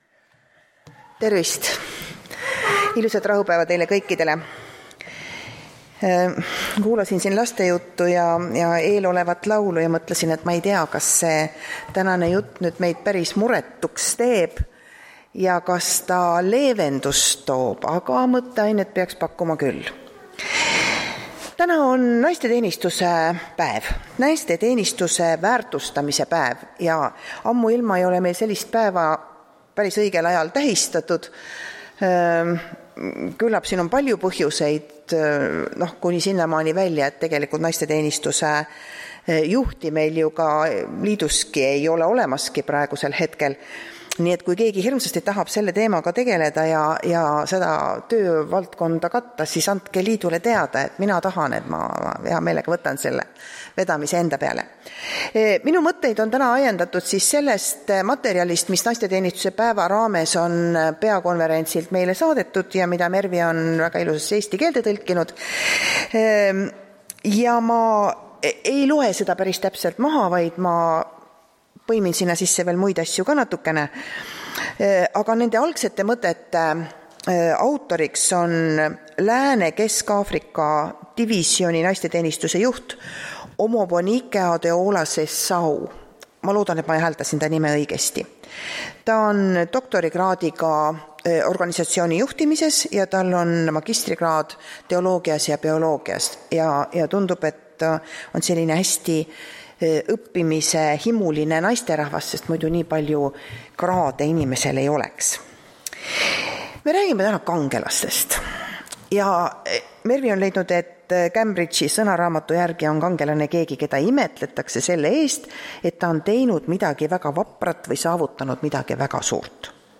Tartu adventkoguduse 11.06.2022 hommikuse teenistuse jutluse helisalvestis.